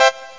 Amiga 8-bit Sampled Voice
SawWave47.mp3